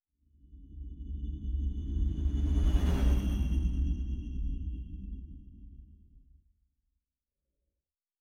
Distant Ship Pass By 5_5.wav